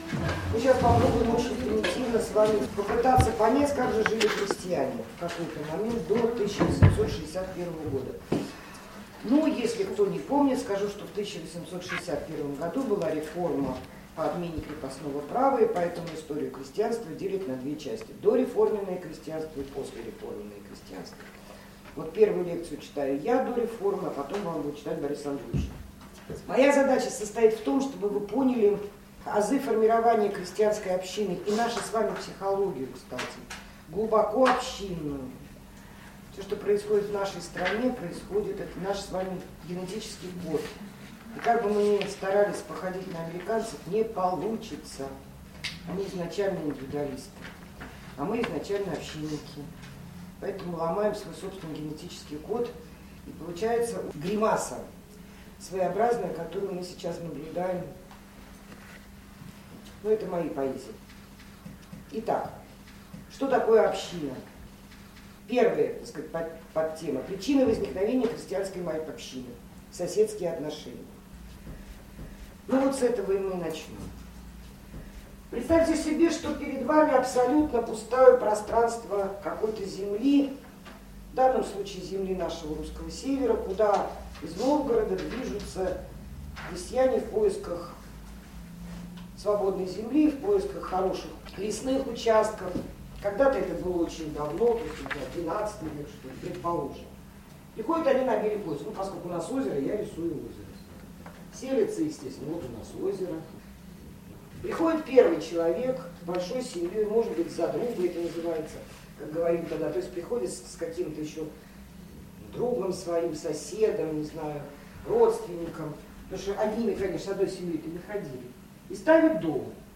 krestyanskaya_obschina_audiozapis_lektsii.mp3